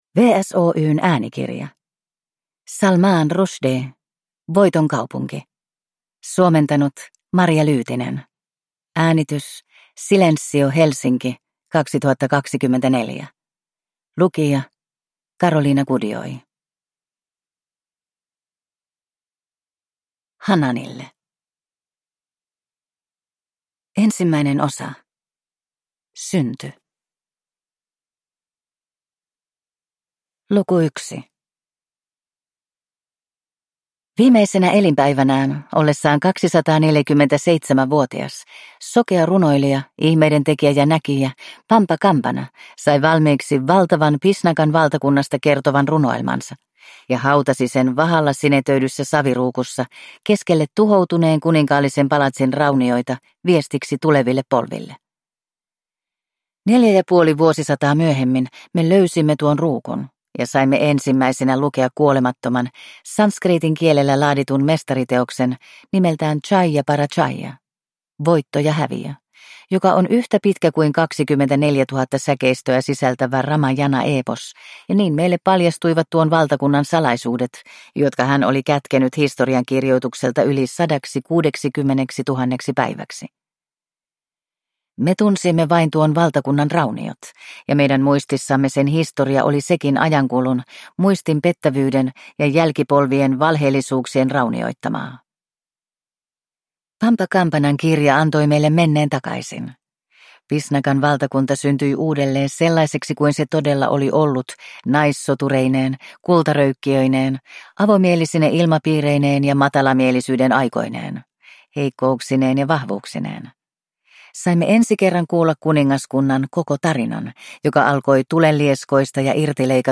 Voiton kaupunki – Ljudbok